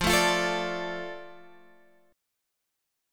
F6 chord